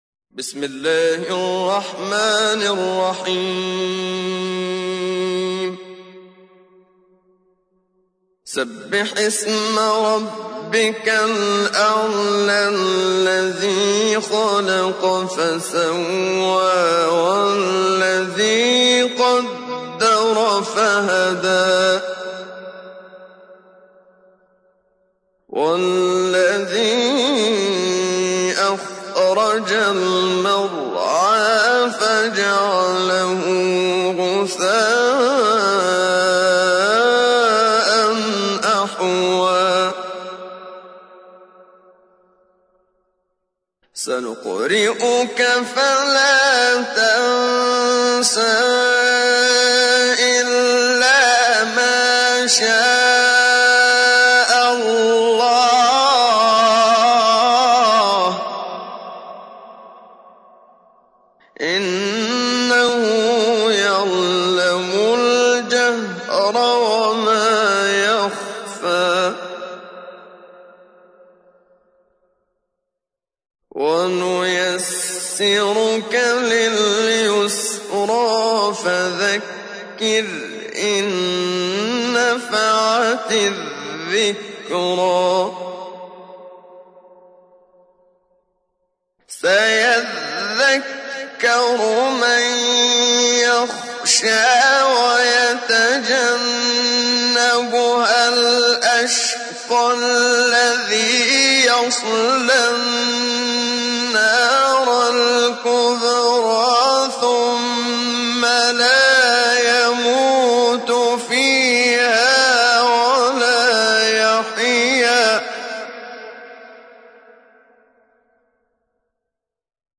تحميل : 87. سورة الأعلى / القارئ محمد صديق المنشاوي / القرآن الكريم / موقع يا حسين